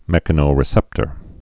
(mĕkə-nō-rĭ-sĕptər)